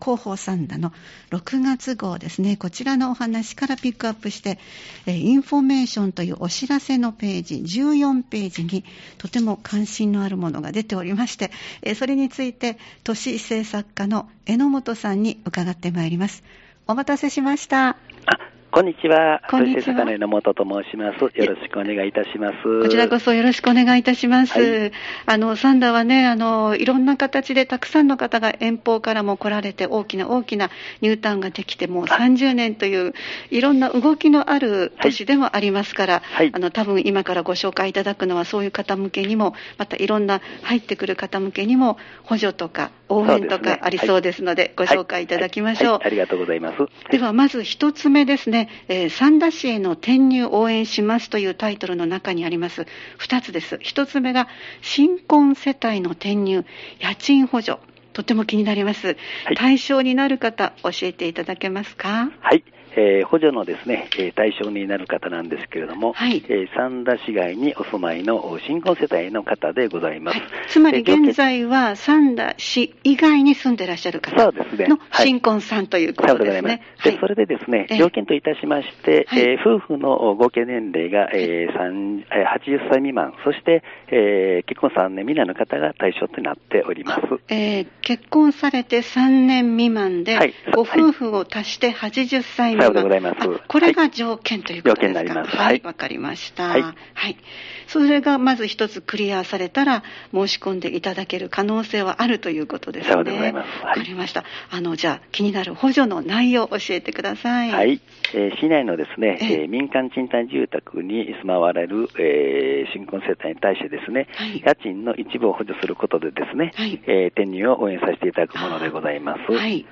アーカイブ放送で聴くことができます！